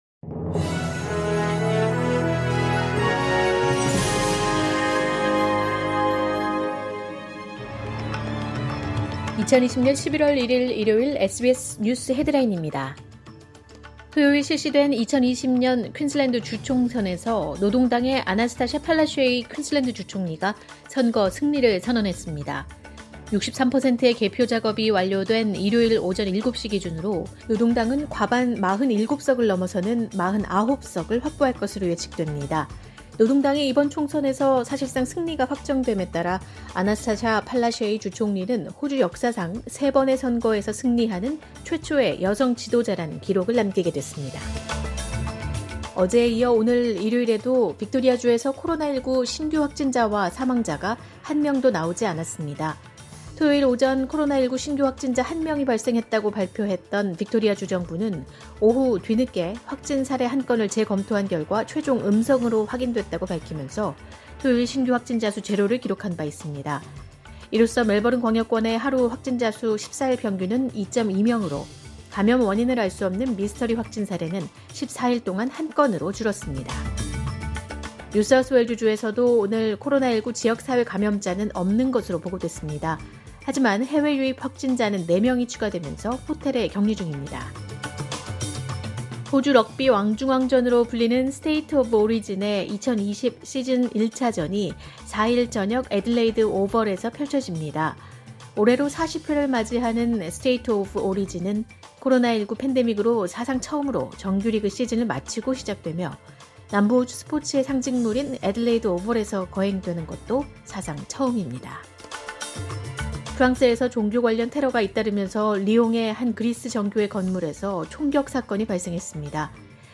SBS News Headlines…2020년 11월 1일 오전 주요 뉴스